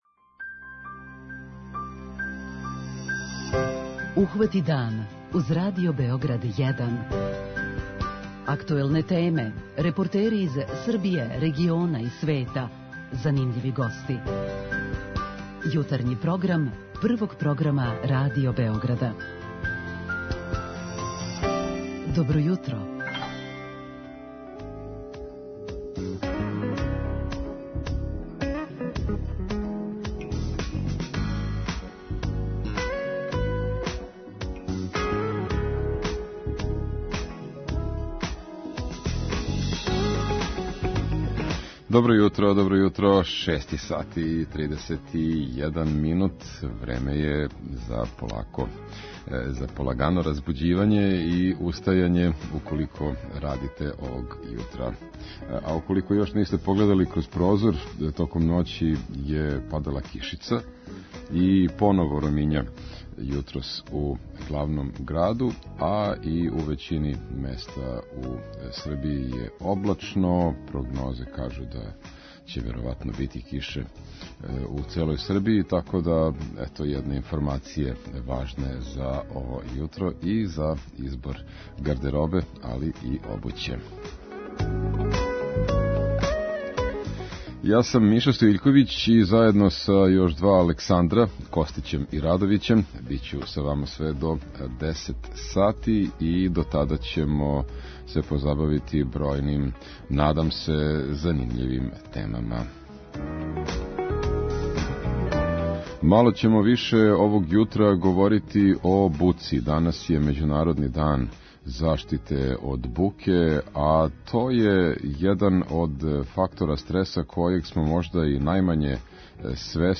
О овој теми разговараћемо и са слушаоцима у редовној рубрици 'Питање јутра'.